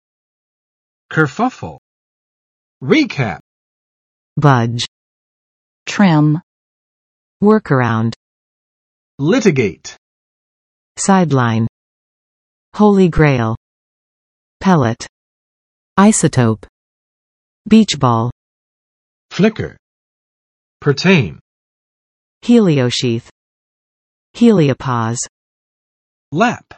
[kɚˋfʌf!] n. 骚乱；混乱